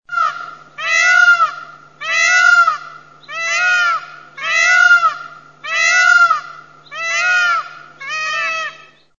Der Pfau ist ein geselliger und zähmbarer Vogel. Für eine Kostprobe des Geschreies einfach auf das Bild klicken.
Pfau.mp3